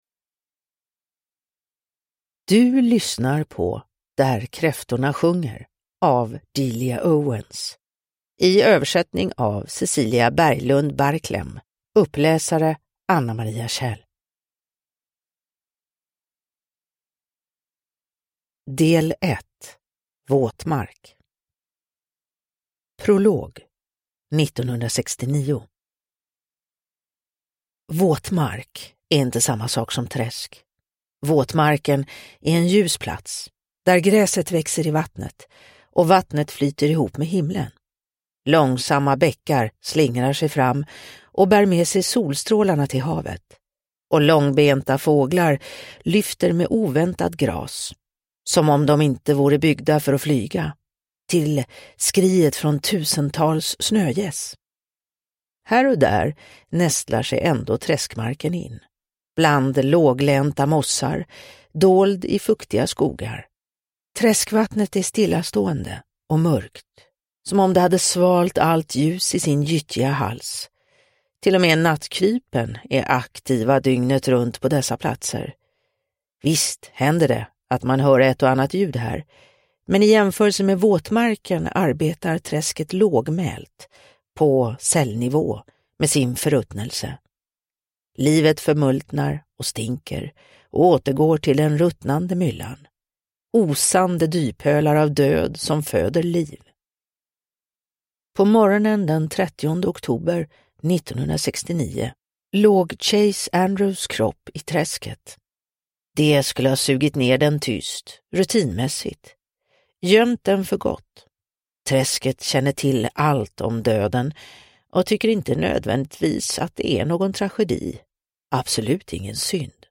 Där kräftorna sjunger – Ljudbok